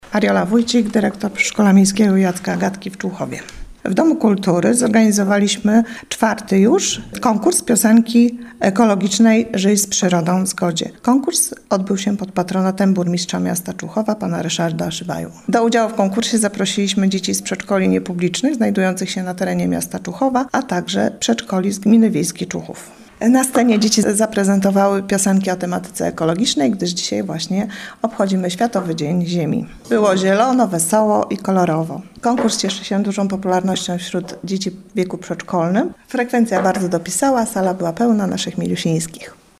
Dzieci prezentują piosenki o tematyce ekologicznej.